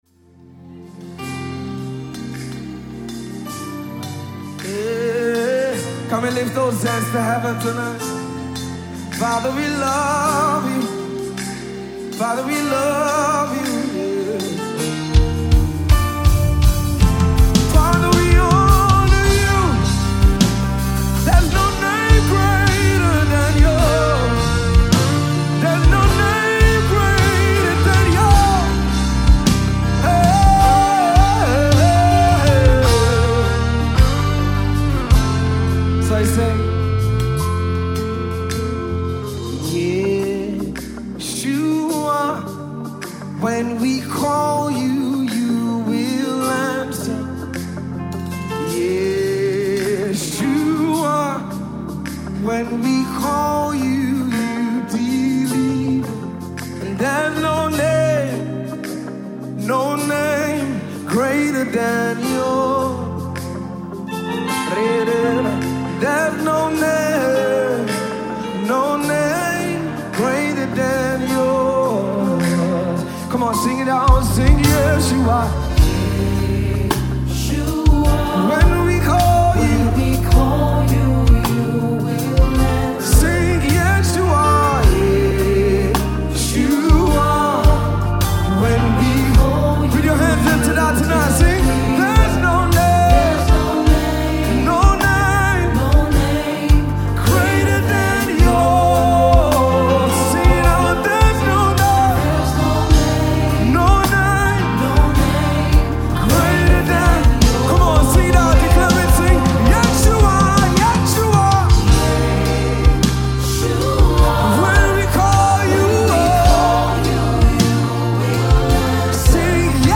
gospel music
Recorded live at David’s Christian Centre, Festac, Lagos.